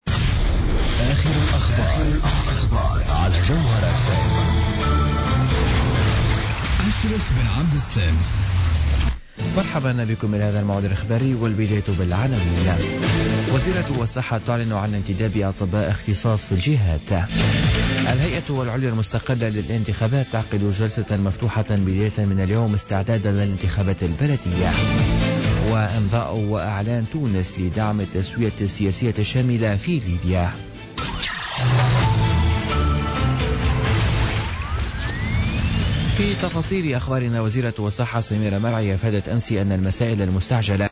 نشرة أخبار منتصف الليل ليوم الثلاثاء 21 فيفري 2017